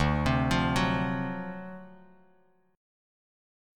D7#9 chord